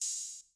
{OpenHat} goosebumps.wav